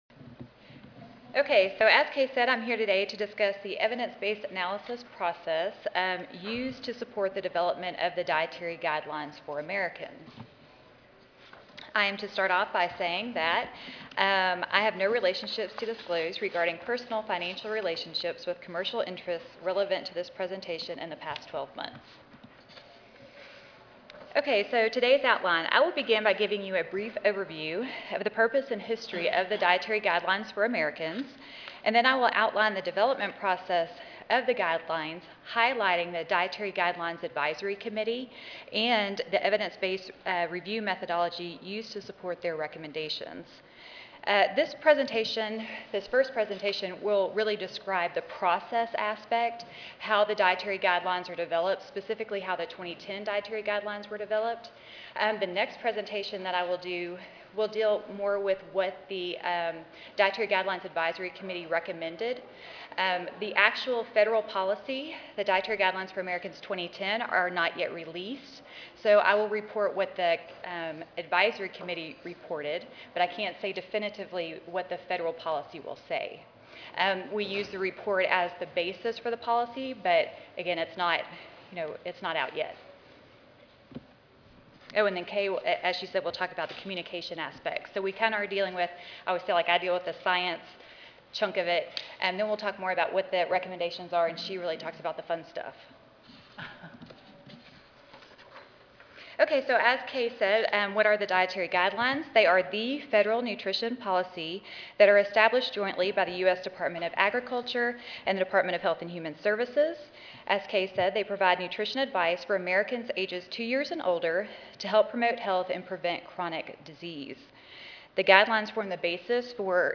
This session will focus on the development of the 2010 Dietary Guidelines, as well as plans for communicating the messages of the Guidelines. Speakers will explain the scientific foundation of the Guidelines, discuss the Federal policy development process, and share formative research findings being used to inform communications strategies. A timeline for release of the 2010 Guidelines and associated communications products will be presented.